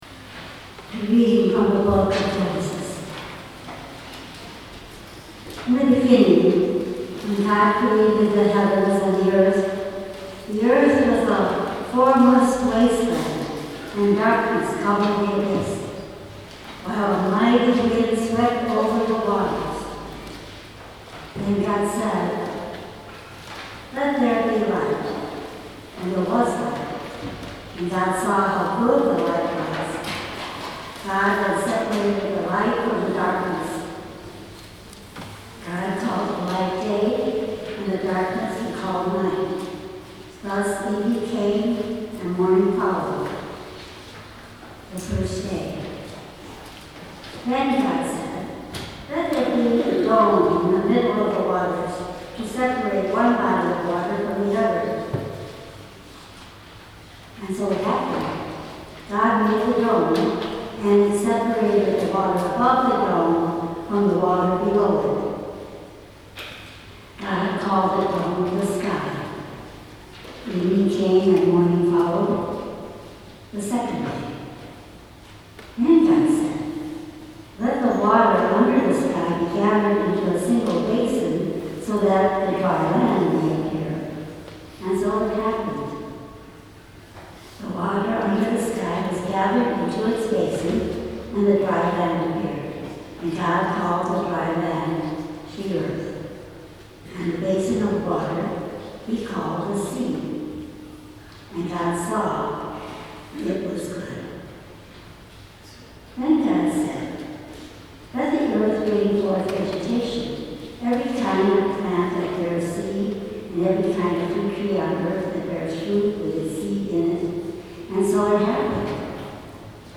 Lakewood Cluster Choirs of Saint Clement, Saint James and Saint Luke Sang this Song
2023 Easter Vigil